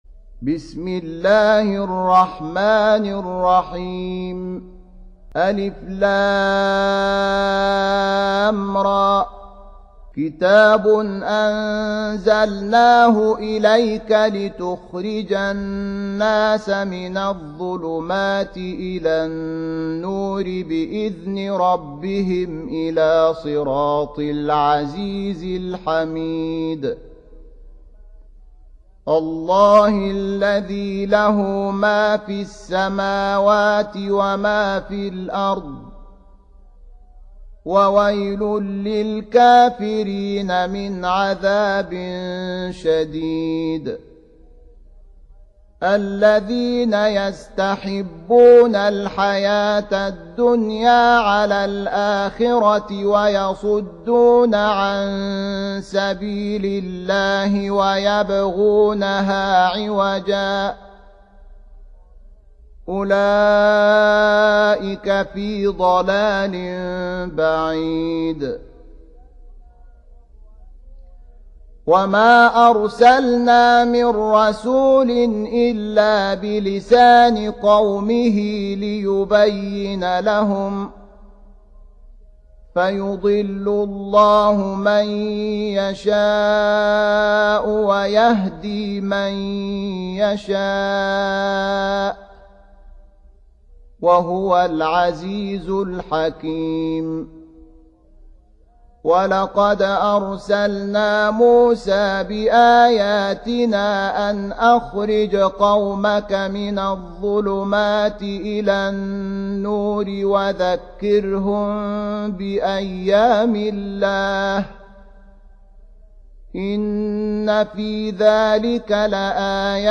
14. Surah Ibrah�m سورة إبراهيم Audio Quran Tarteel Recitation
Surah Repeating تكرار السورة Download Surah حمّل السورة Reciting Murattalah Audio for 14. Surah Ibrah�m سورة إبراهيم N.B *Surah Includes Al-Basmalah Reciters Sequents تتابع التلاوات Reciters Repeats تكرار التلاوات